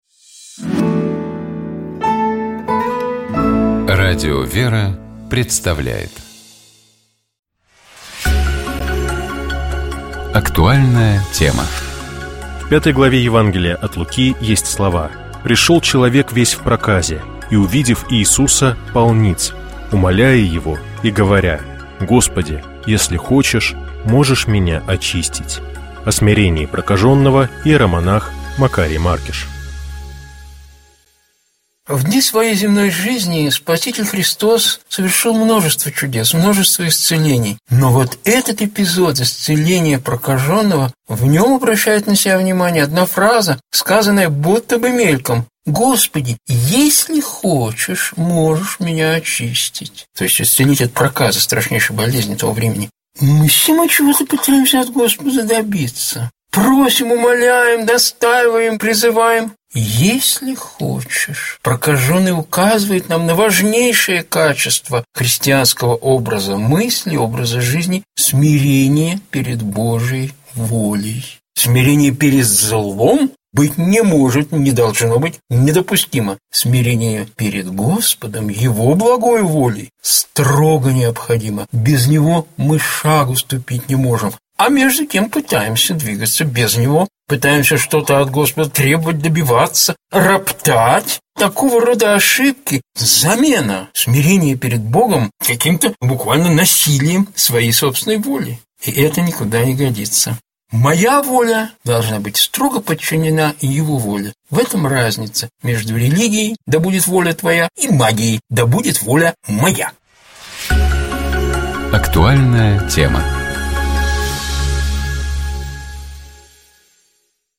С вами епископ Переславский и Угличский Феоктист.
Псалом 108. Богослужебные чтения Скачать 19.02.2026 Поделиться Здравствуйте!